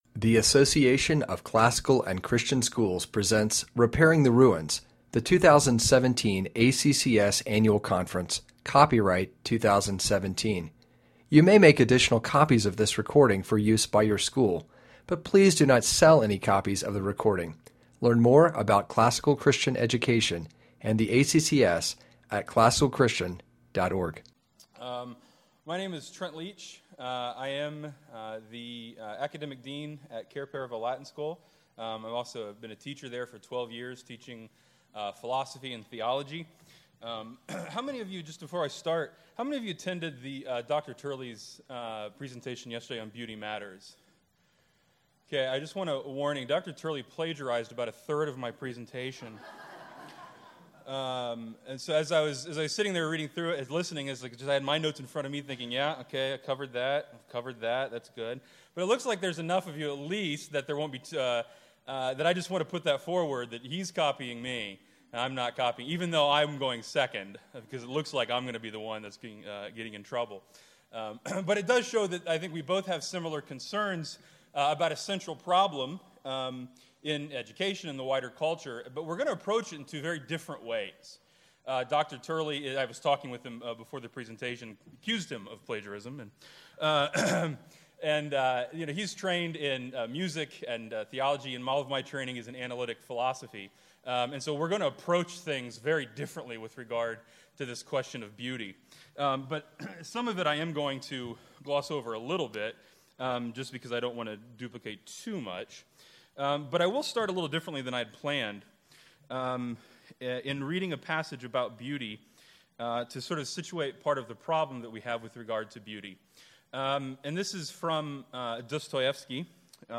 2017 Workshop Talk | 1:02:59 | All Grade Levels, General Classroom